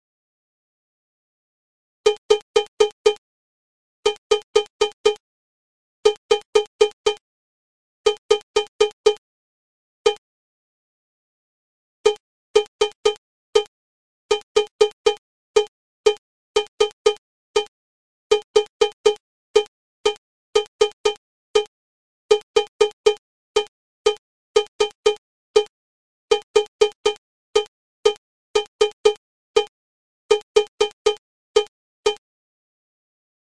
Funky-agogos.mp3